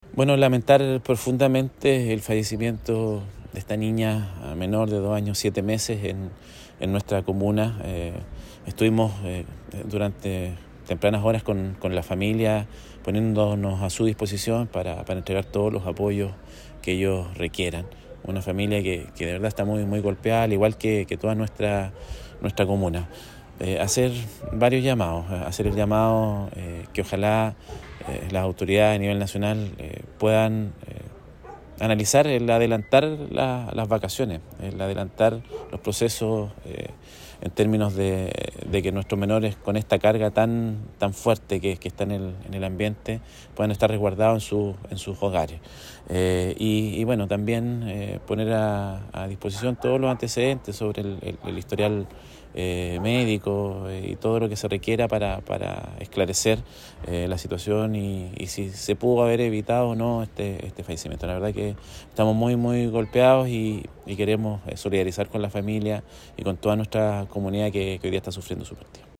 Desde el municipio, el alcalde de Monte Patria, Cristian Herrera Peña, quien temprano por la mañana se reunió con la familia de la menor fallecida para brindar todo el apoyo necesario, indicó